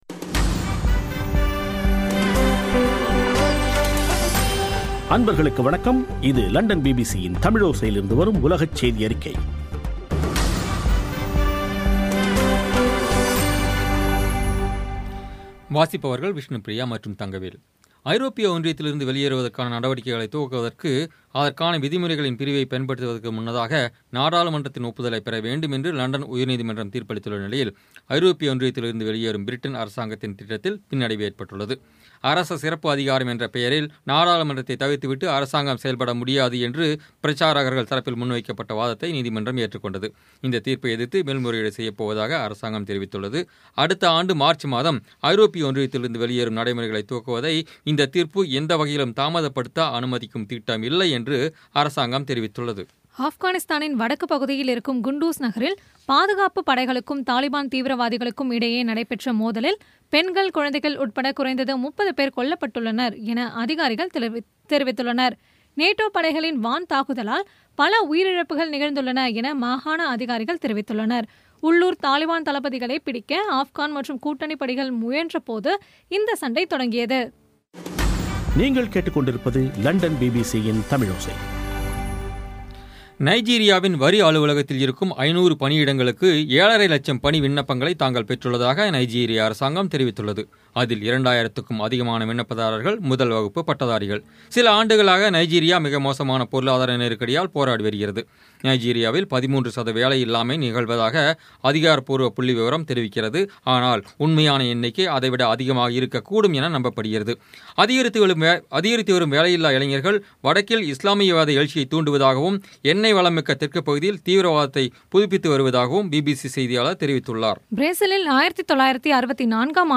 பி பி சி தமிழோசை செய்தியறிக்கை (03/11/2016)